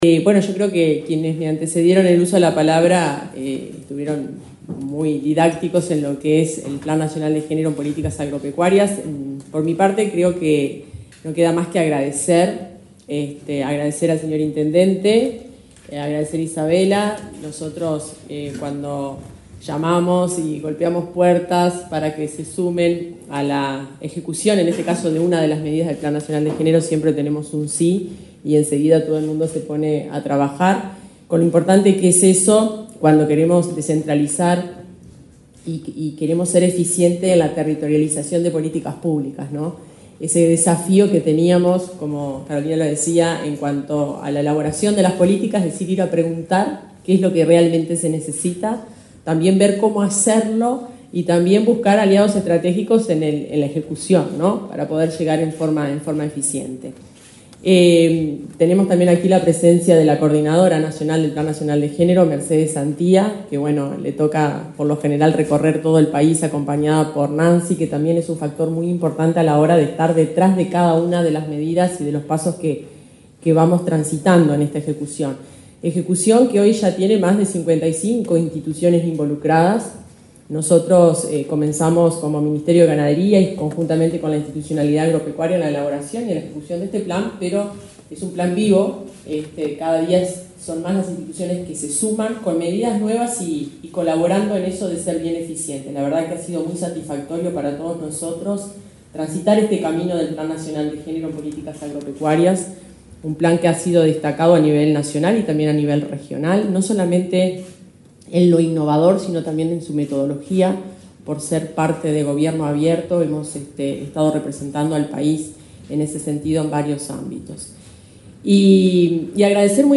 Palabras de la directora general del MGAP, Fernanda Maldonado